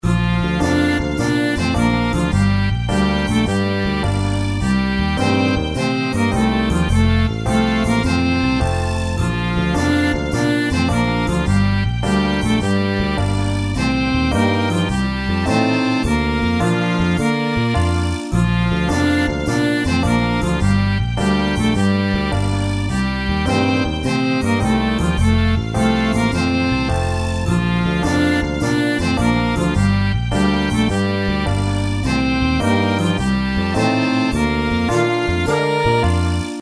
楽曲の曲調： SOFT
コミカル  ほのぼの  爽やか